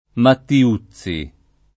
Mattiuzzi